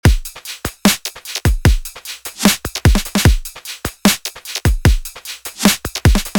Drums (Before)
Drums-Before.mp3